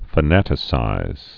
(fə-nătĭ-sīz)